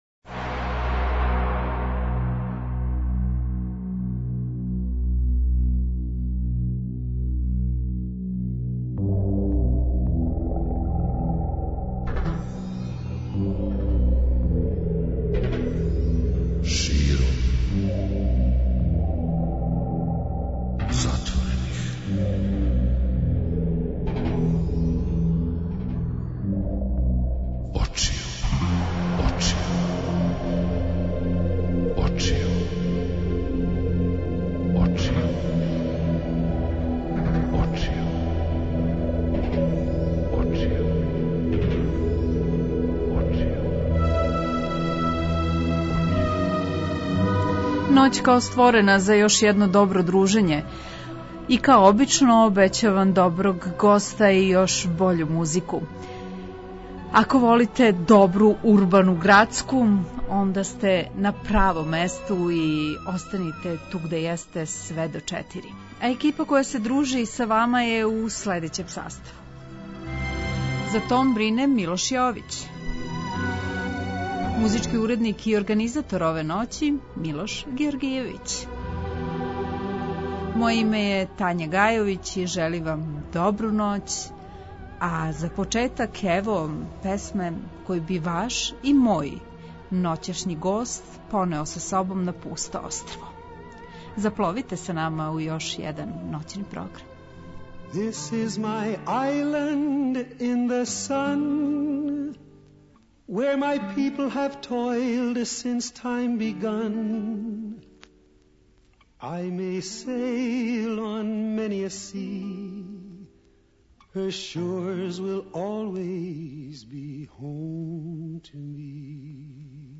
Гост: Владимир Ђурић - Ђура Морнар, музичар